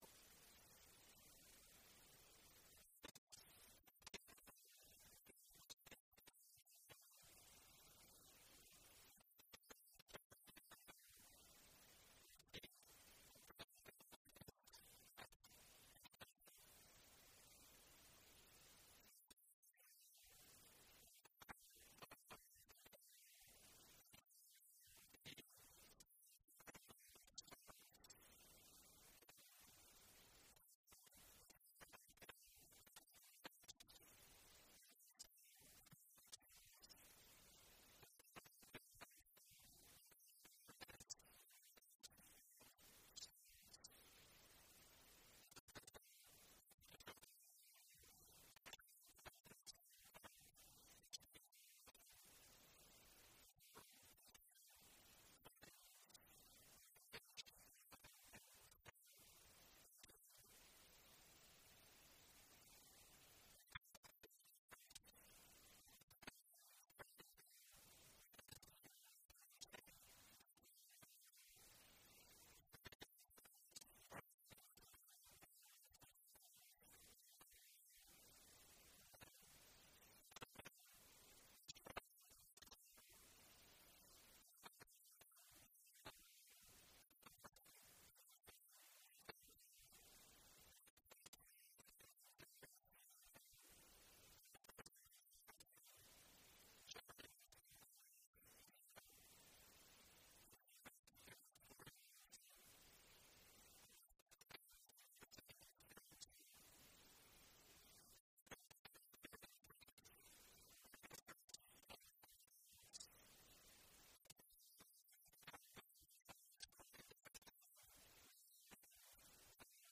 public ios_share Tenth Church Sermons chevron_right Naomi's Redemption Oct 19, 2014 A sermon about famine, flight, and the social risks Naomi faced after losing family and land. It follows Ruth's loyalty and bold choices as she gleans in Boaz's field. The talk highlights Boaz's kindness, cultural laws about kinsman-redeemers, and how providence can weave planning and chance.